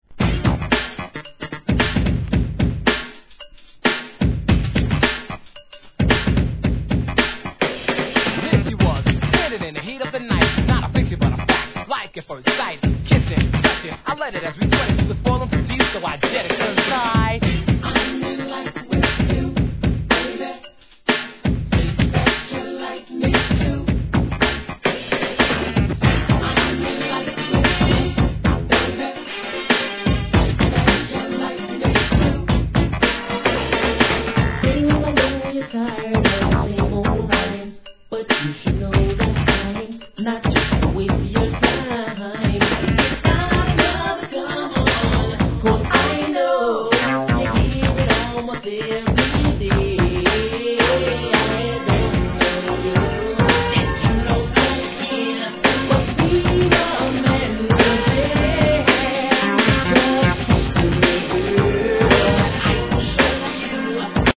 HIP HOP/R&B
FUNK〜NEW JACK SWINGにカヴァー曲のメドレーまで!!